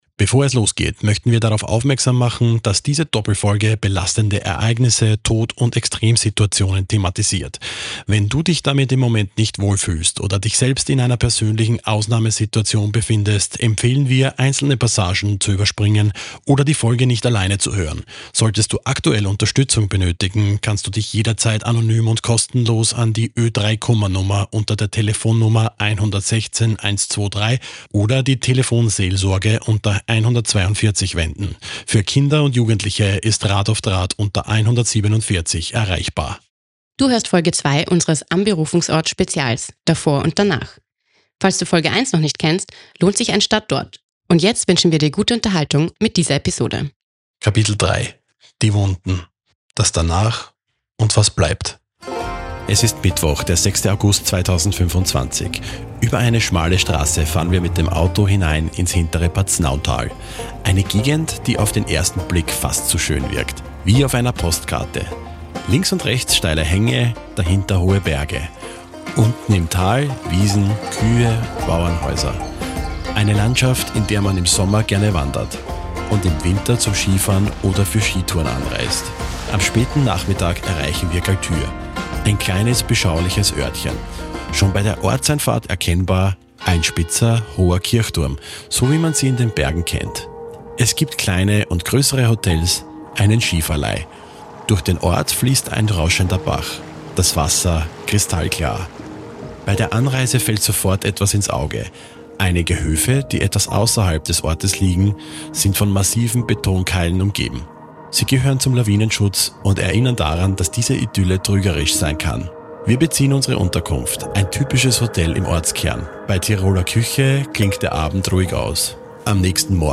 Ausgehend vom Grubenunglück in Lassing und der Lawinenkatastrophe in Galtür schlagen wir den Bogen bis in die Gegenwart nach Graz. Wir sprechen mit Zeitzeug:innen, Überlebenden, Helfer:innen und Entscheidungsträger:innen über das Erlebte – und über die oft unsichtbare Phase danach.